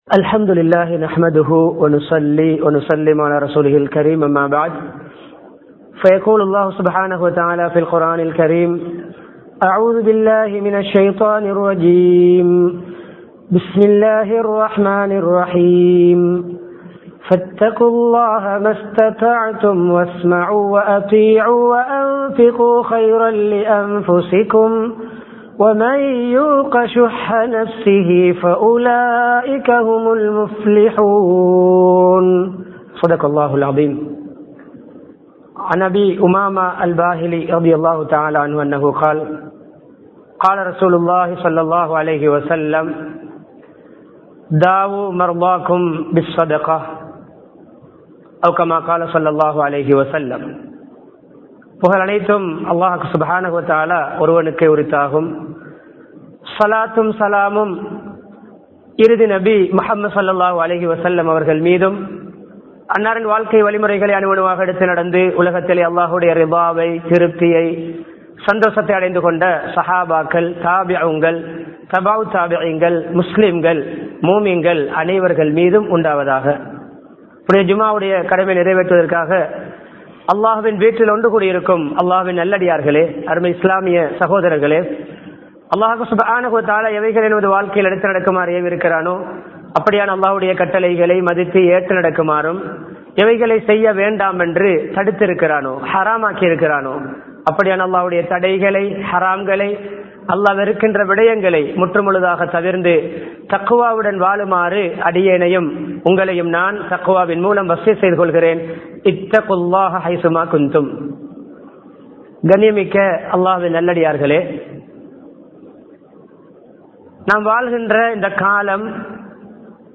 நோயாளியும் ஸதகாவும் | Audio Bayans | All Ceylon Muslim Youth Community | Addalaichenai
Dehiwela, Kawdana Road Jumua Masjidh